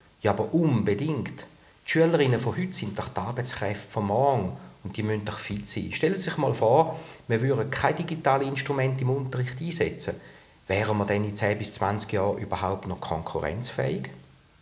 Dieses Interview gibt es auch auf HOCHDEUTSCH!!!